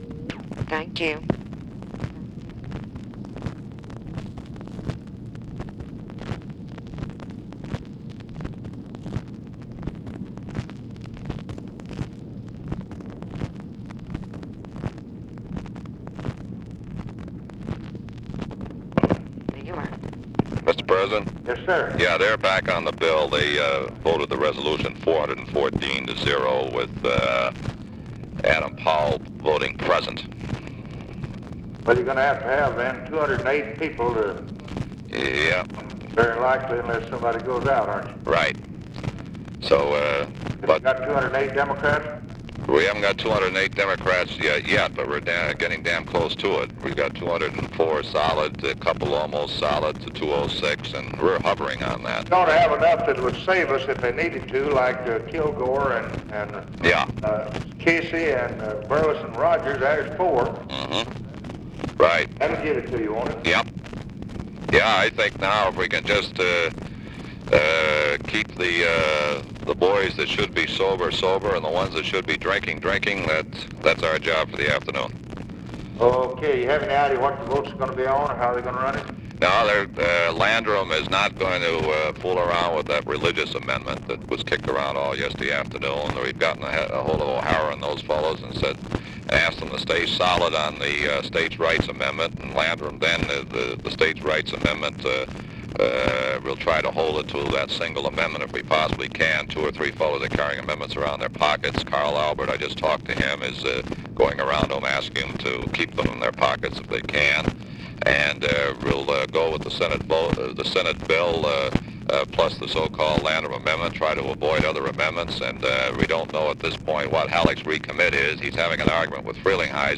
Conversation with LARRY O'BRIEN, August 7, 1964
Secret White House Tapes